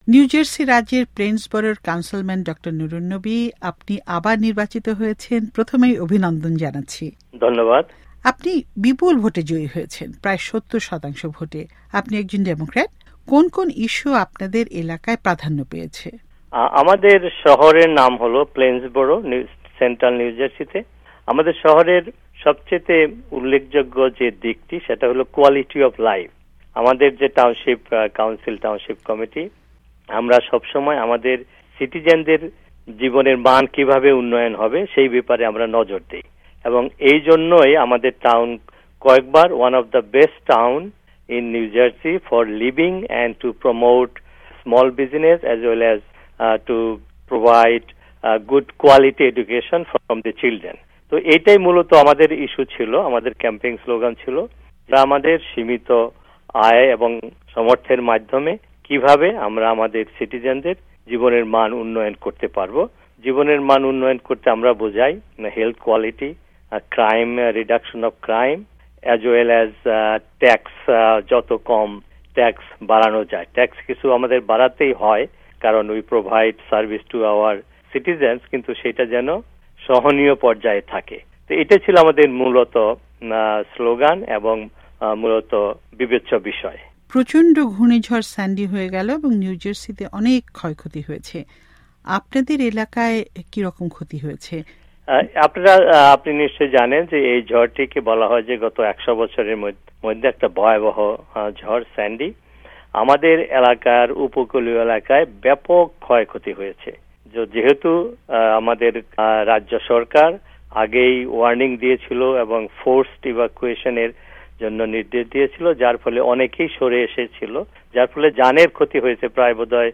কাউন্সিলম্যান নবীর সাক্ষাৎকার